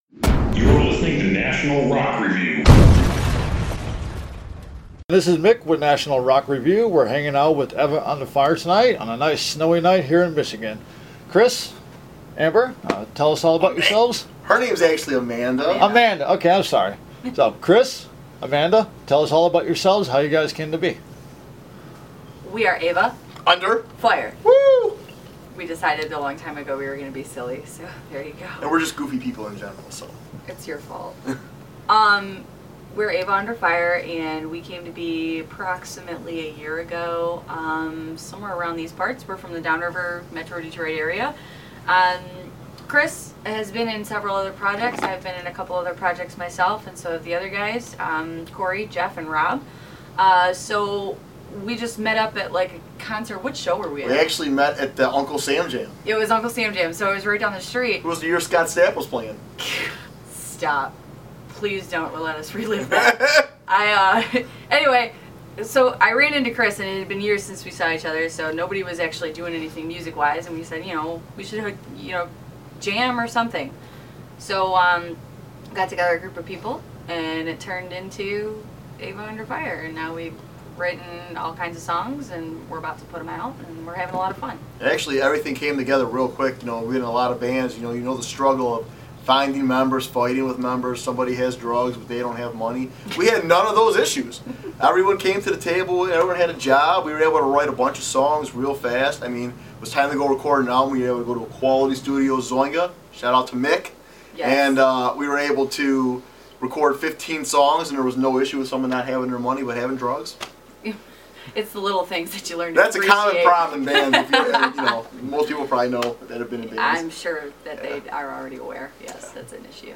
Eva Under Fire sat down with National Rock Review to discuss their upcoming CD release show for their new album, Anchors.